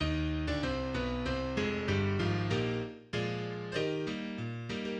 ジャンル 行進曲